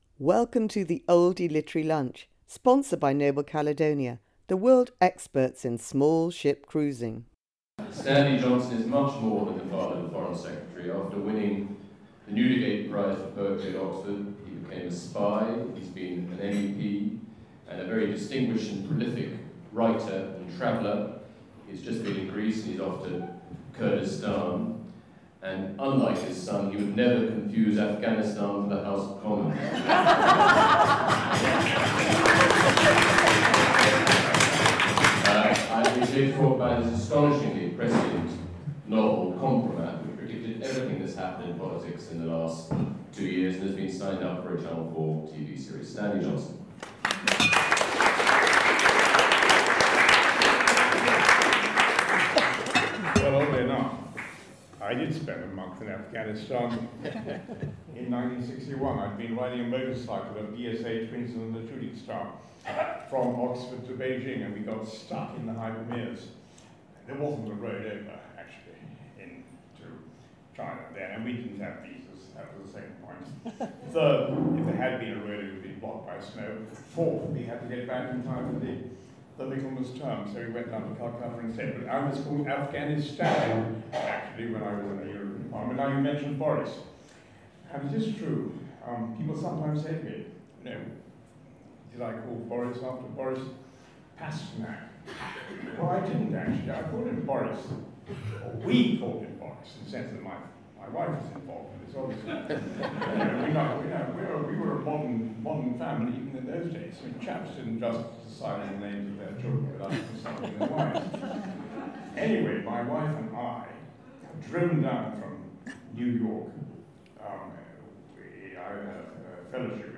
He talks at Chichester Cathedral, an Oldie lunch in partnership with the Literary festival.